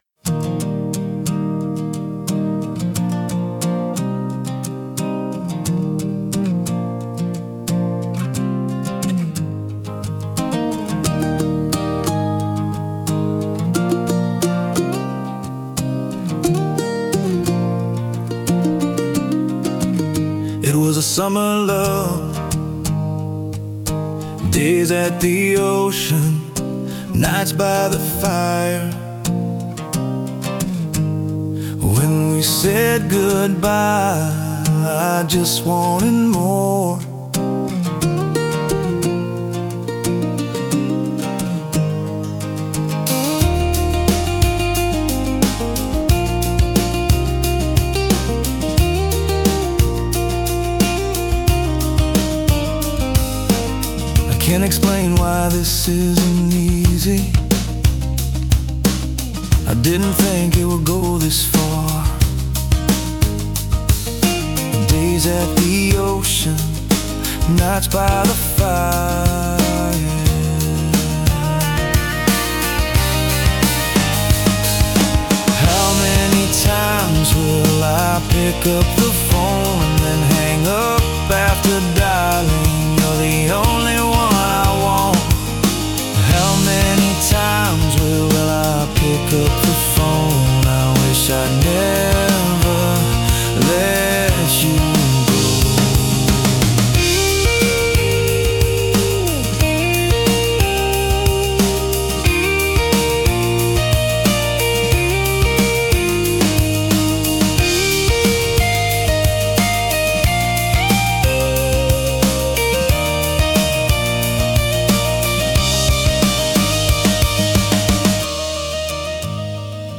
Contemporary acoustic pop track